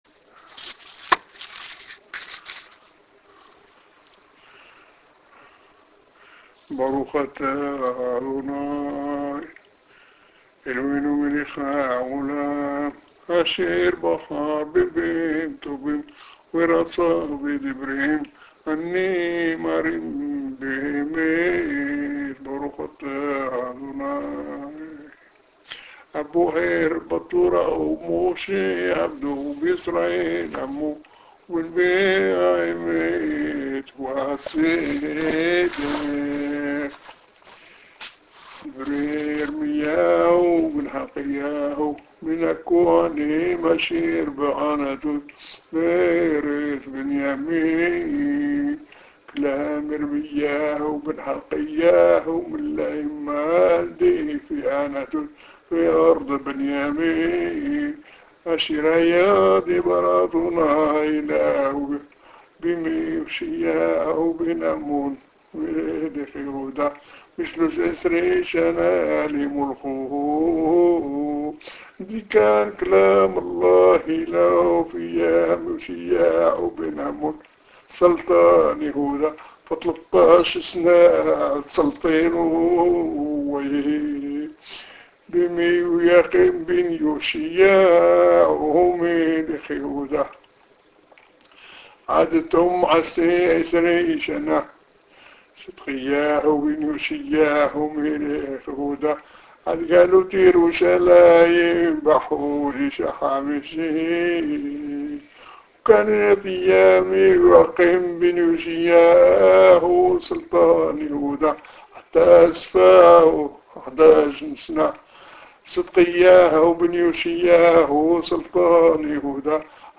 HAFTARA